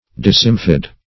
Search Result for " decemfid" : The Collaborative International Dictionary of English v.0.48: Decemfid \De*cem"fid\ (d[-e]*s[e^]m"f[i^]d), a. [L. decem ten + root of findere to cleave.]